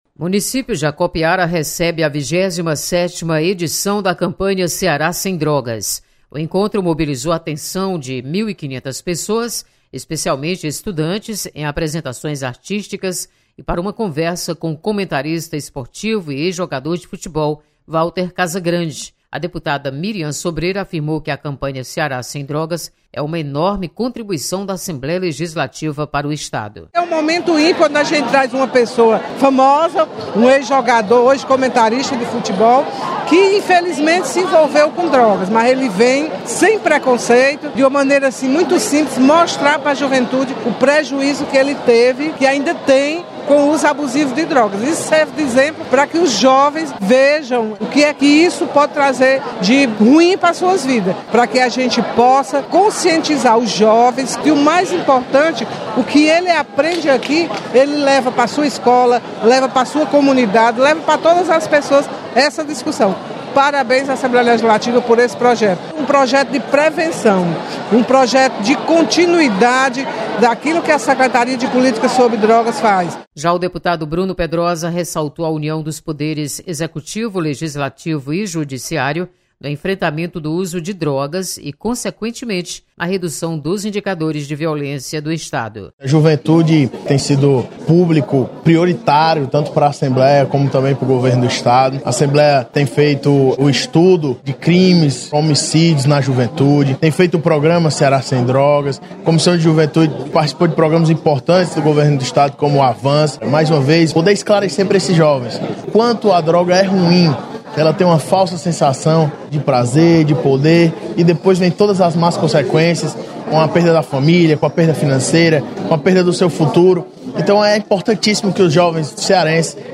Você está aqui: Início Comunicação Rádio FM Assembleia Notícias Ceará Sem Drogas